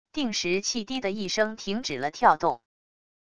定时器滴的一声停止了跳动wav音频